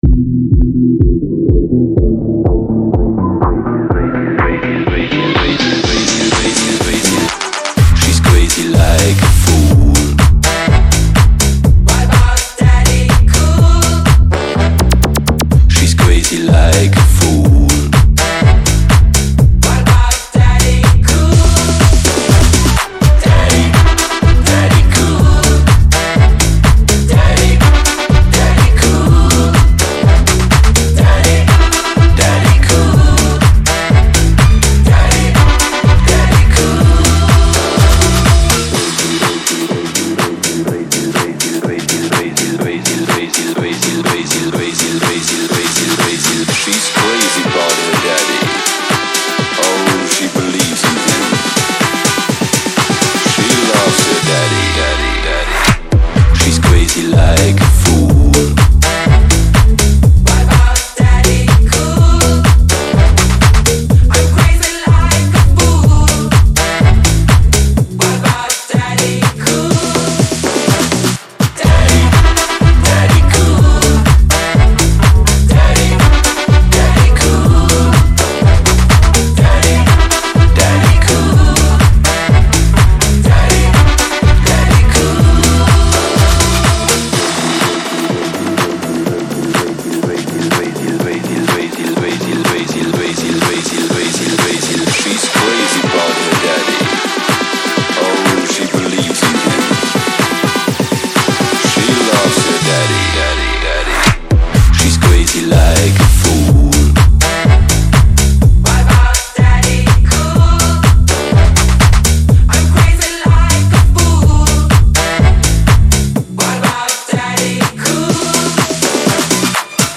Disco, Pop